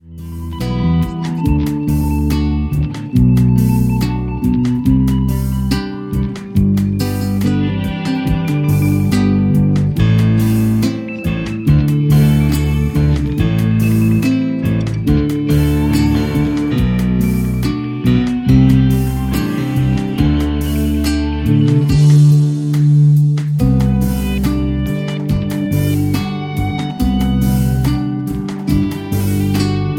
Backing track Karaoke
Rock, 1990s